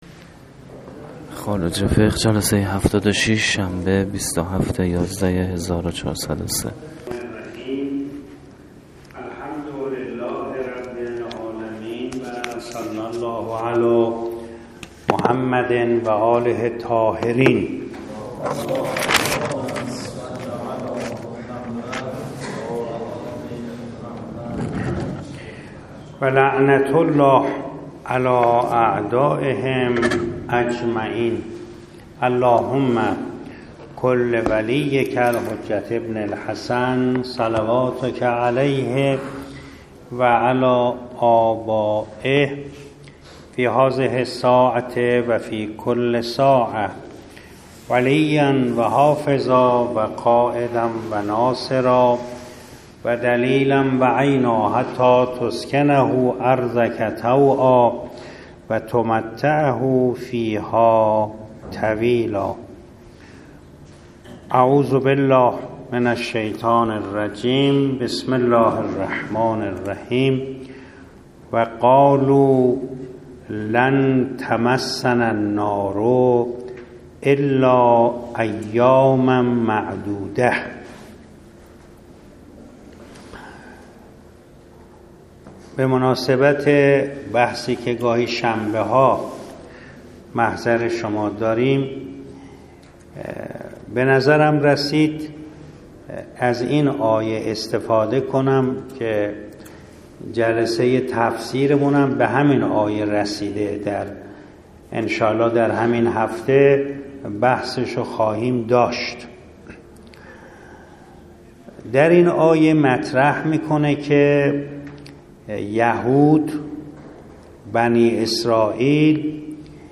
درس اخلاق